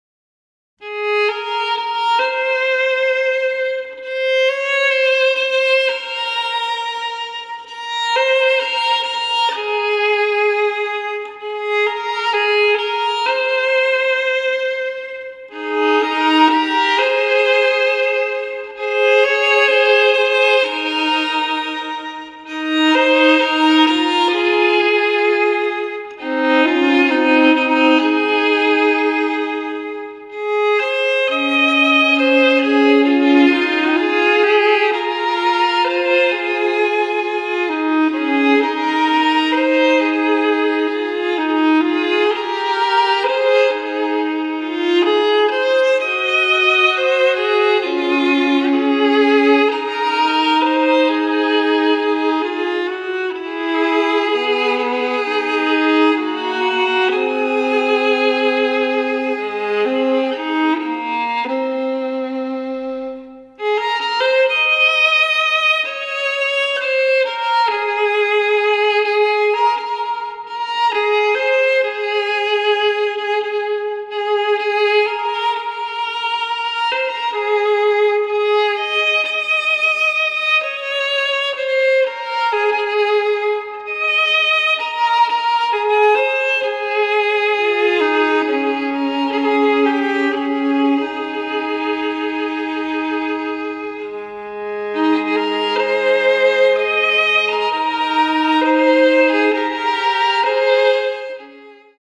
ヴァイオリン
カラスの鳴き声が入っており、飛び立つというギミックがあるんですが、